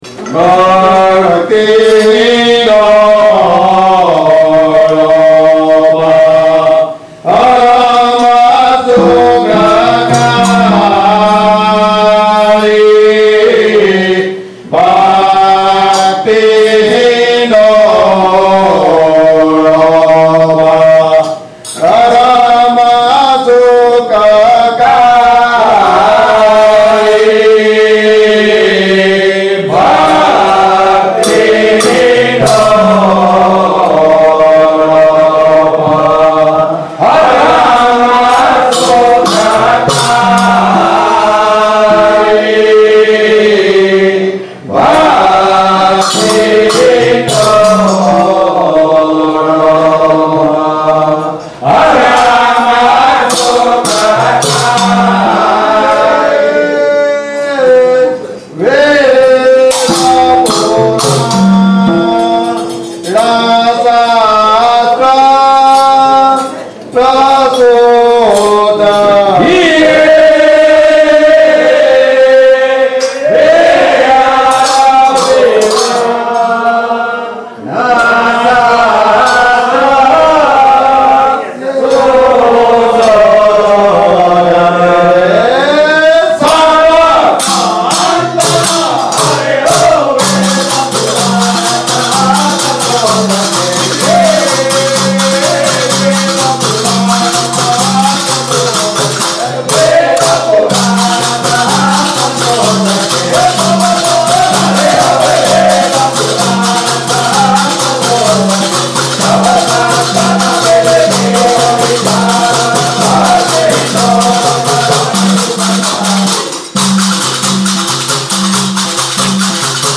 Ramkabir Bhajans ( RAMKABIR BHAJAN રામકબીર ભજન ) is dedicated to the traditional bhajans of Shree Ramkabir Bhakta Samaj.